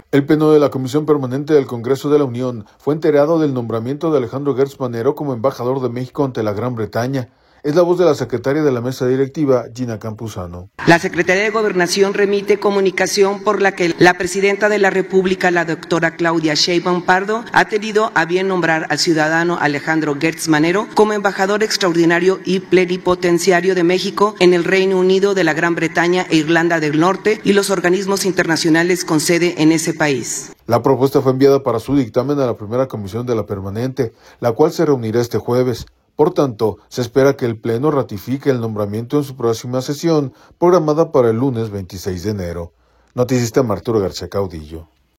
audio El Pleno de la Comisión Permanente del Congreso de la Unión fue enterado del nombramiento de Alejandro Gertz Manero como embajador de México ante la Gran Bretaña. Es la voz de la secretaria de la Mesa Directiva, Gina Campuzano.